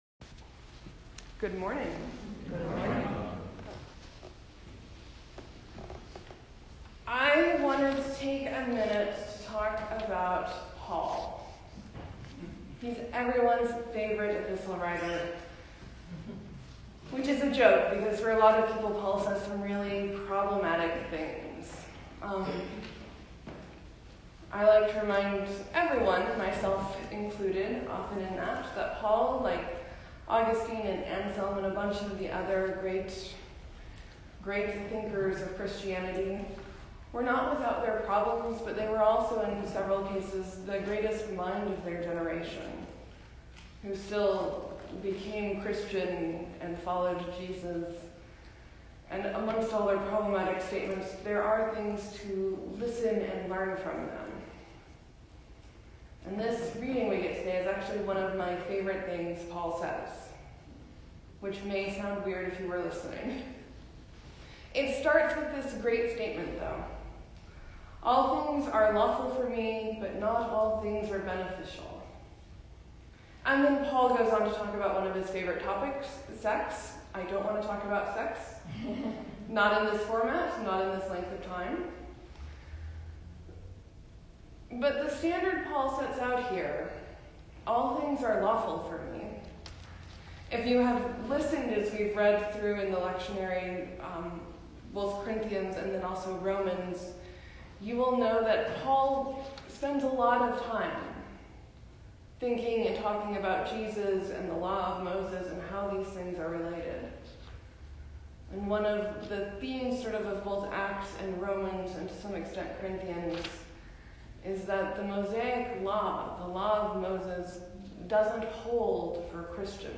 Sermon: Jesus greets Nathanael in an interesting way and why Nathanael has been giving me hope this week.